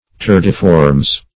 Search Result for " turdiformes" : The Collaborative International Dictionary of English v.0.48: Turdiformes \Tur`di*for"mes\, n. pl.